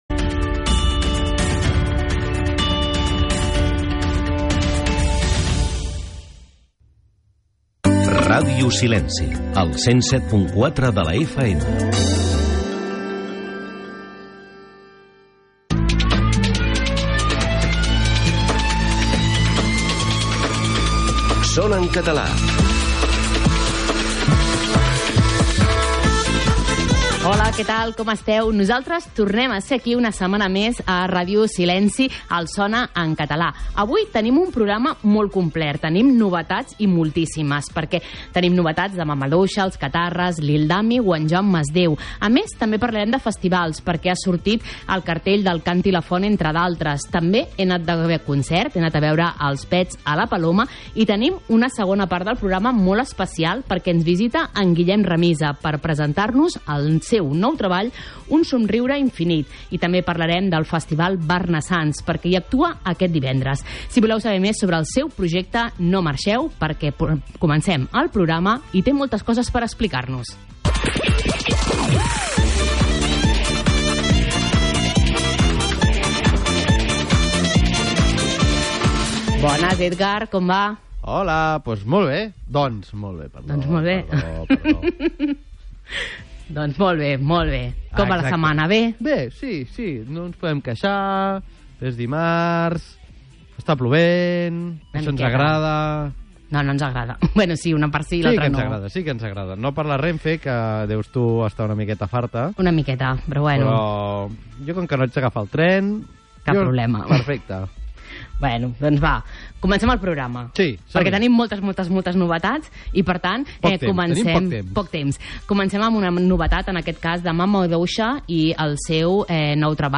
Una hora de música en català amb cançons de tots els temps i estils. Història, actualitat, cròniques de concerts, curiositats, reportatges, entrevistes...